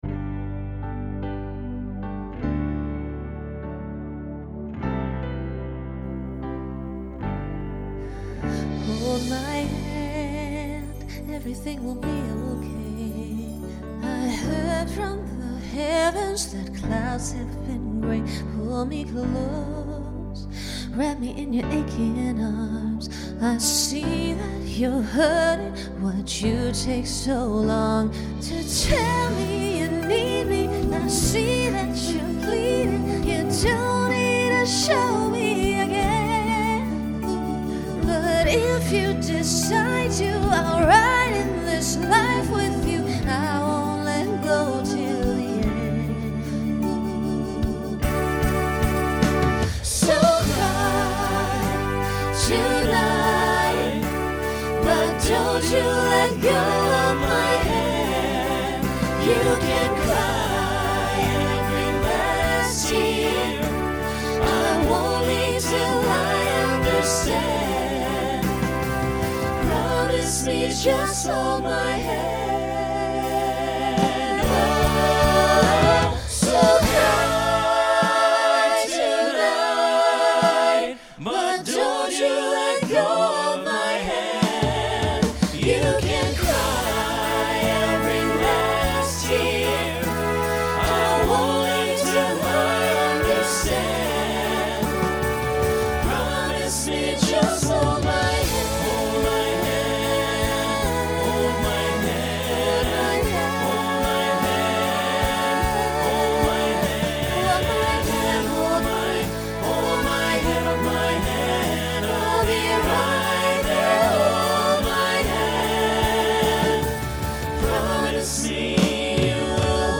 2020s Genre Broadway/Film , Pop/Dance
Costume Change Voicing Mixed